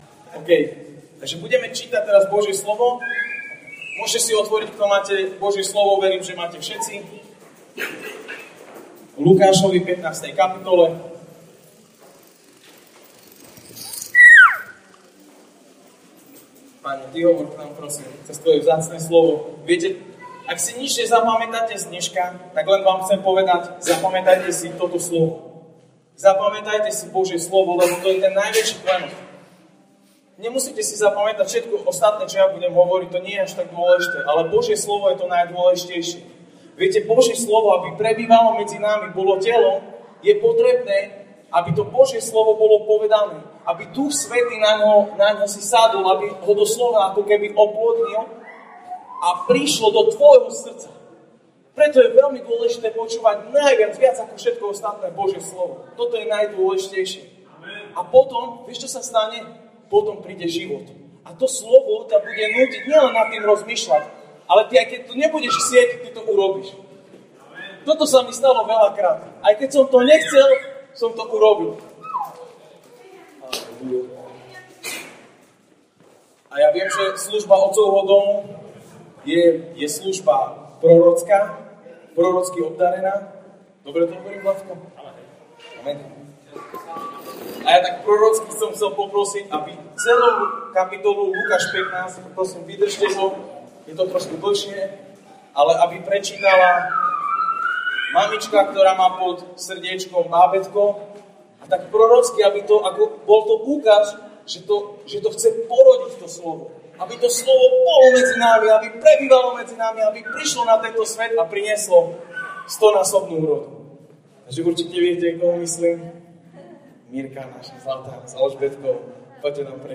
Sobotné slovo z CHATovačky Otcovho domu v Čičmanoch. Ospravedlňujeme sa za zníženú kvalitu nahrávky.